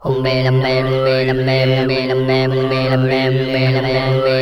MUMBLERS  -L.wav